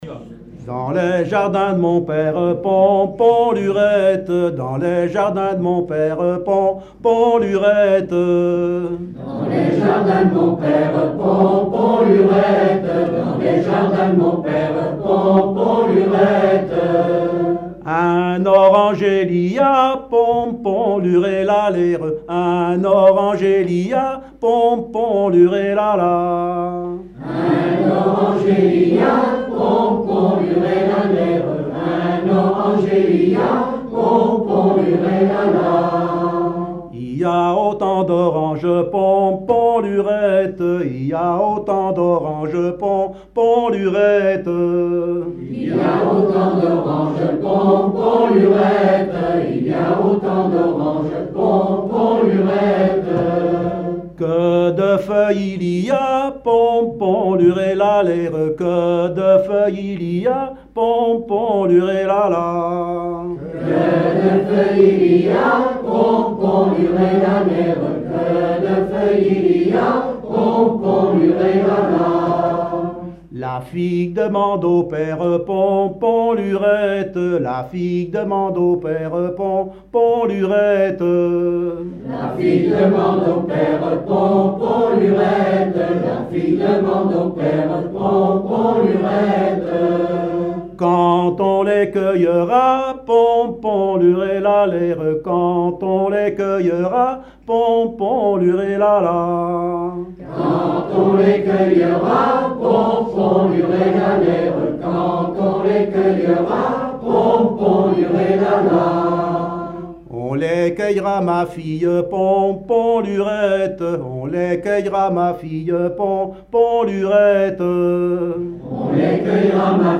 Genre laisse
Veillée (version Revox)
Pièce musicale inédite